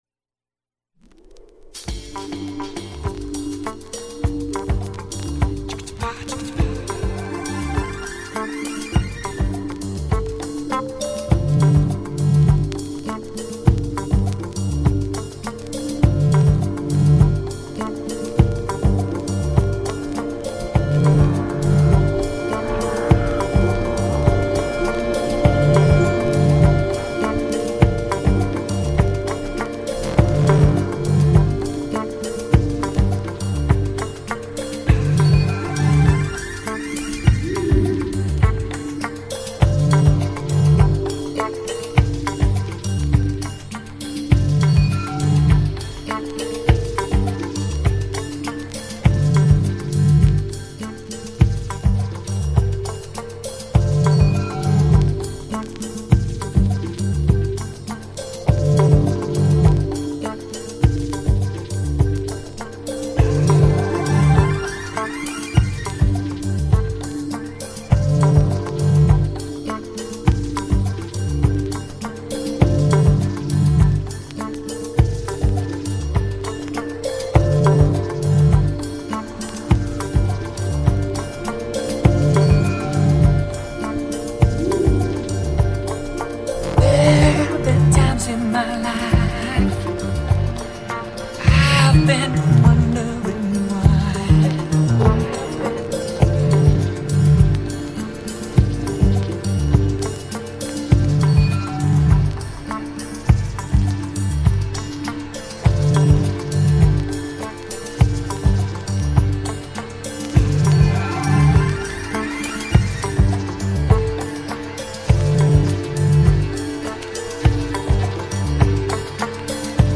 Alt Disco / Boogie